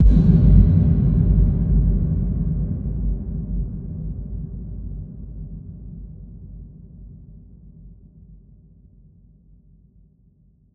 Big Drum Hit 06.wav